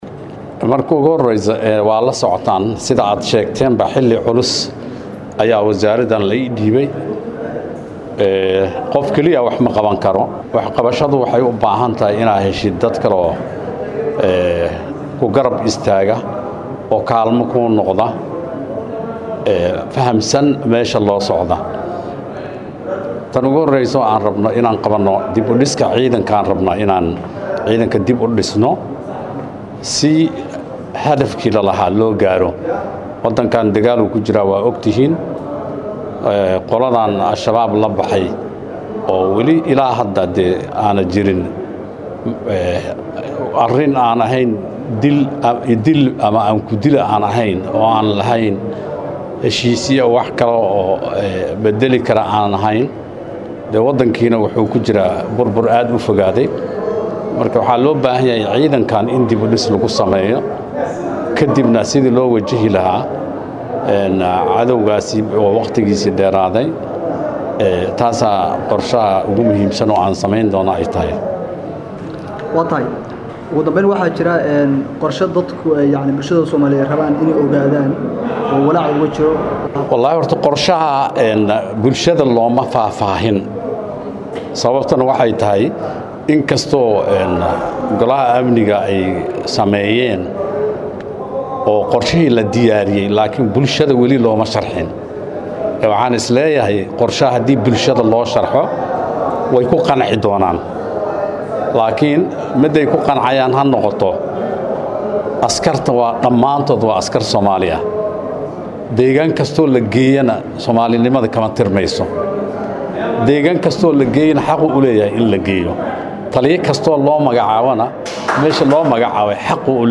Maxamed Mursal Sheekh Wasiirka Wasaaradda Gaashaandhiga Xukumadda Federaalka Soomaaliya ayaa Arintaas ku sheegay wareysi uu siiyay Warbaahinta Qaran,wuxuuna xusay in ay ka go,an tahay Wasaaradda Gaashaandhiga Xukuumadda Soomaaliya in Guud ahaanba Dalka laga saaro Maleeshiyada argagixisada SHabaab.
Dhageyso-Codka-wasir-maxamed-Mursal.mp3